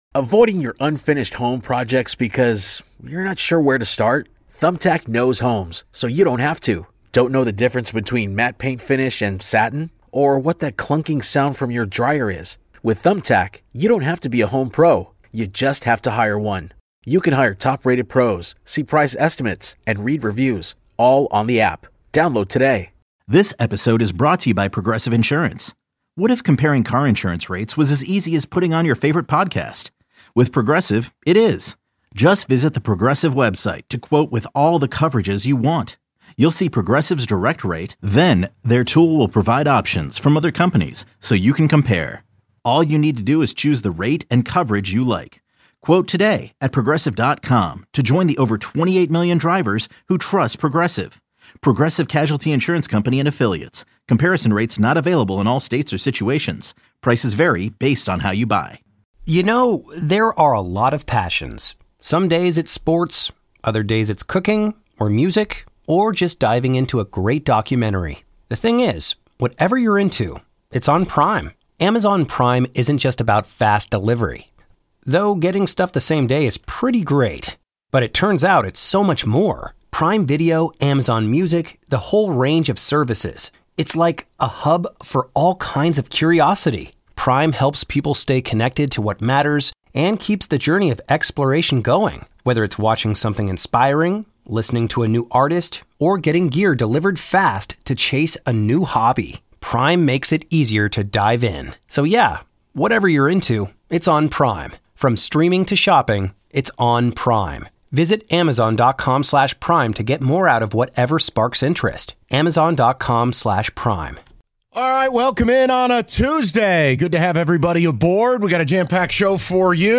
They take your calls and read your ticket texts on the topic